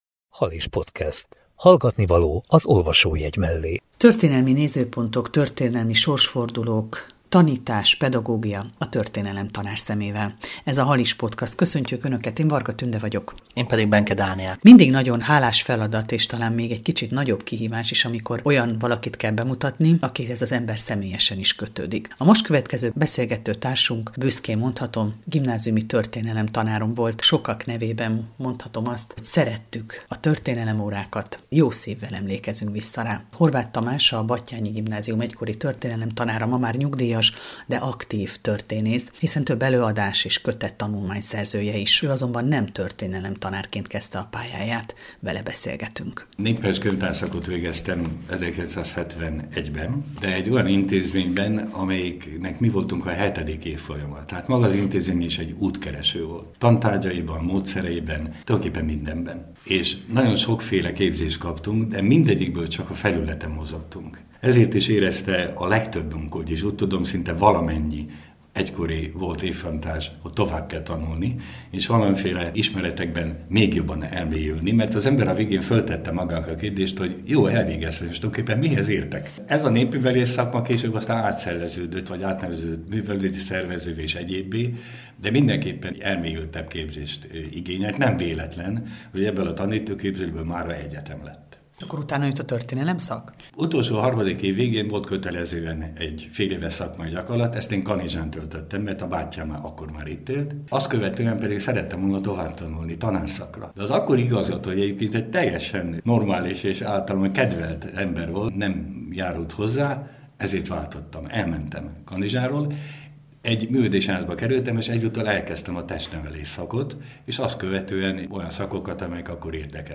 Halis Podcast 62 - Beszélgetés